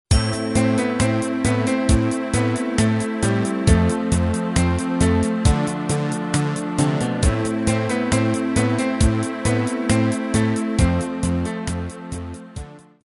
Заказ полифонической версии:
• Пример мелодии содержит искажения (писк).